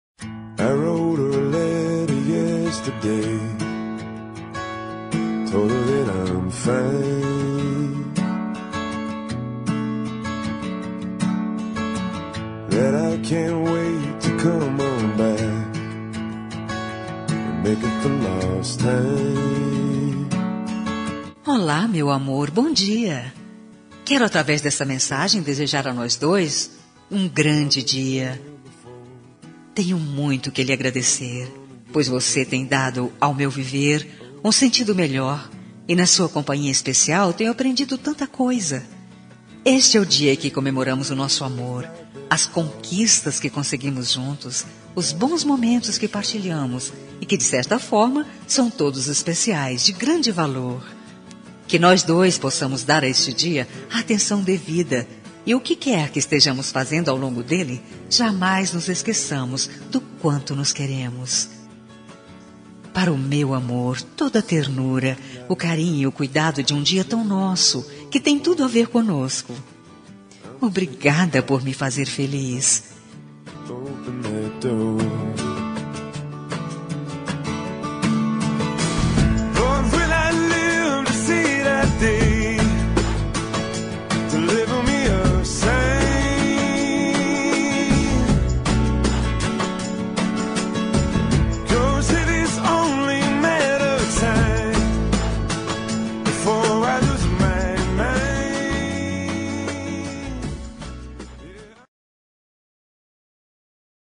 Tempo de Namoro – Voz Feminina – Cód: 706